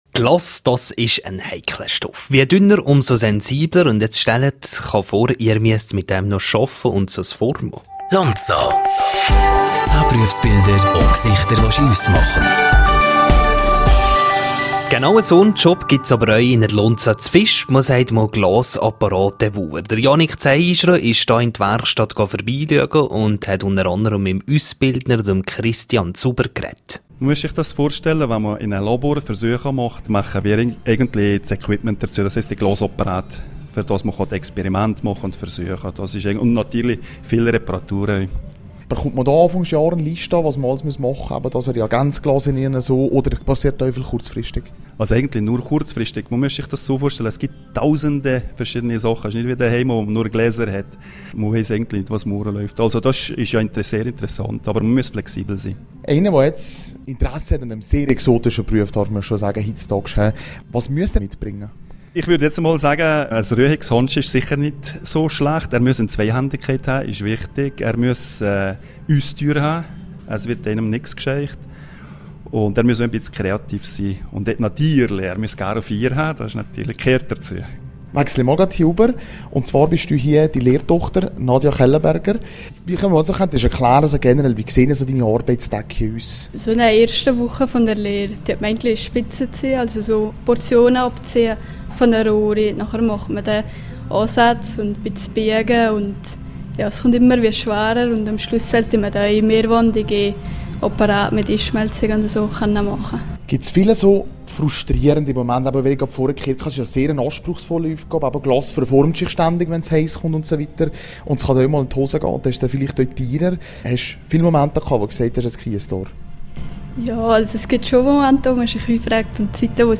rro Interview